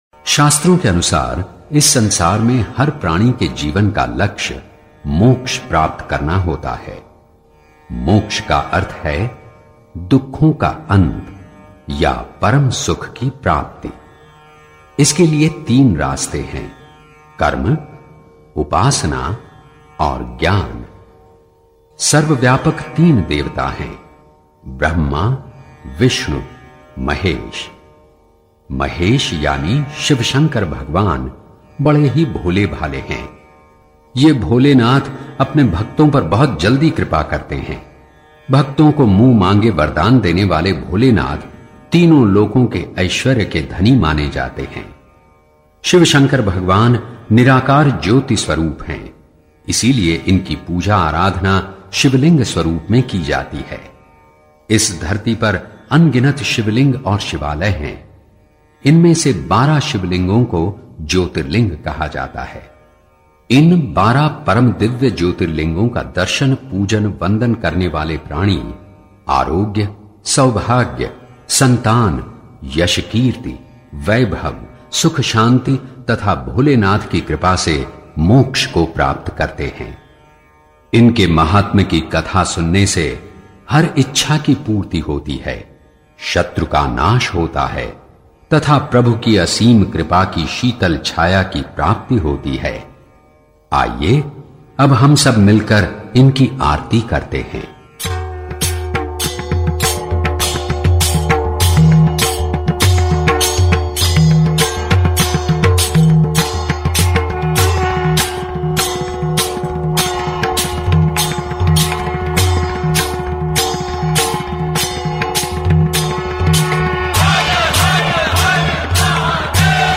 Devotional Songs > Shiv (Bholenath) Bhajans